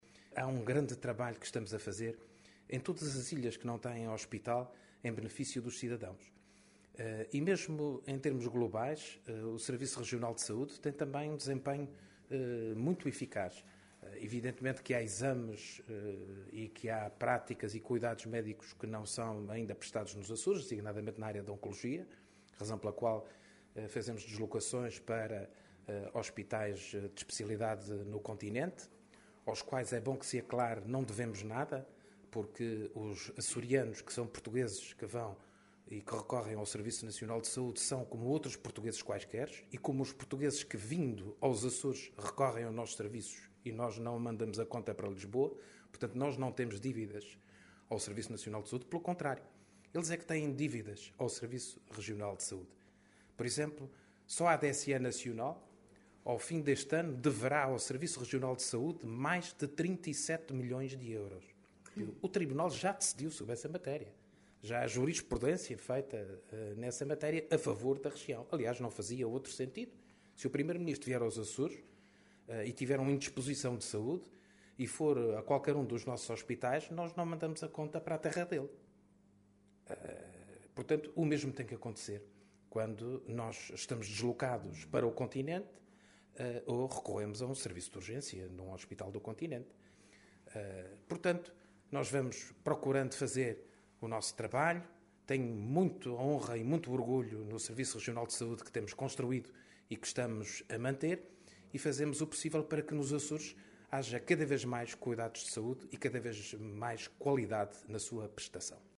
O Presidente do Governo Regional falava pouco antes de uma reunião que teve, esta manhã, com os responsáveis pelo Centro de Saúde das Flores – ilha em que o Governo se encontra para uma visita de dois dias –, reunião que serviu para aferir o funcionamento daquele centro e verificar se há algo que possa ser feito para melhorar o seu desempenho.